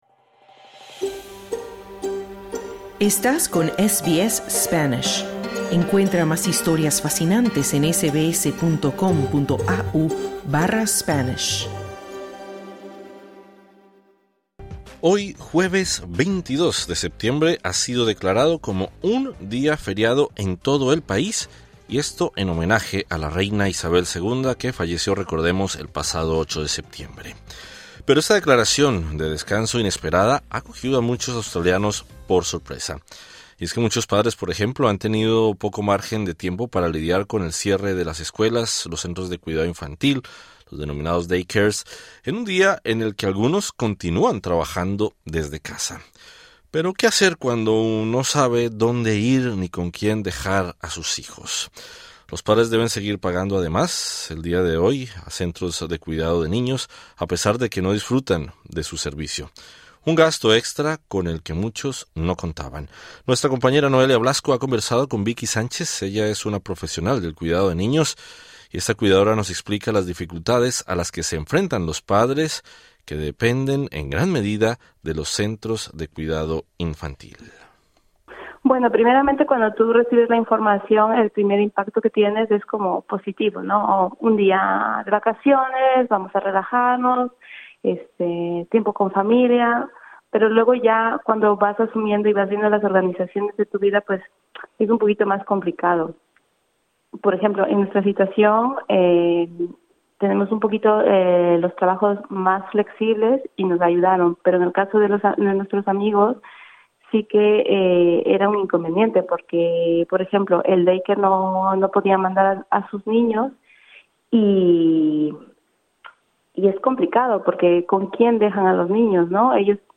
La respuesta fue no. Esta cuidadora nos explica las dificultades a las que se enfrentan los papás que dependen en gran medida de los centros infantiles.